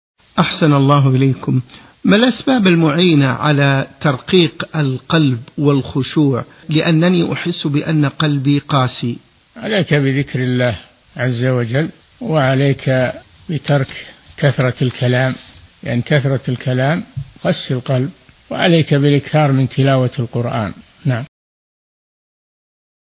الشيخ: صالح بن فوزان الفوزان القسم: من مواعظ أهل العلم